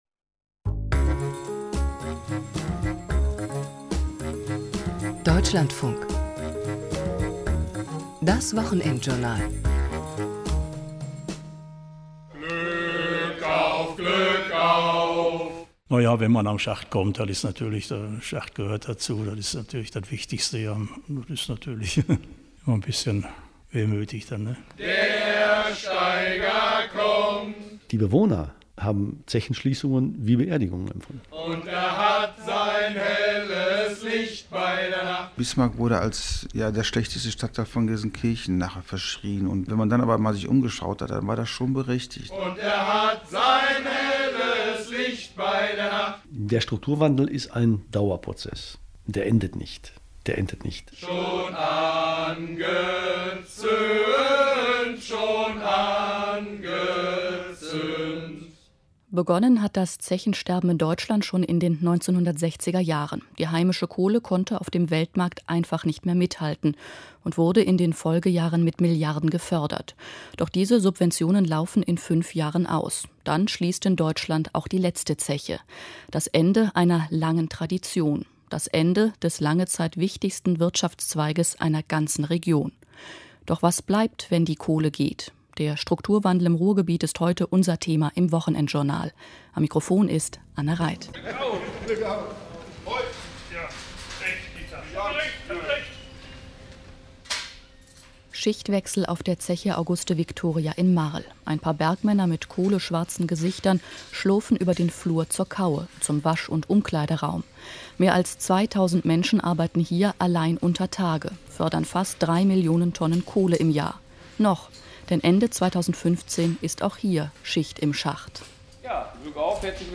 DLF-Reportage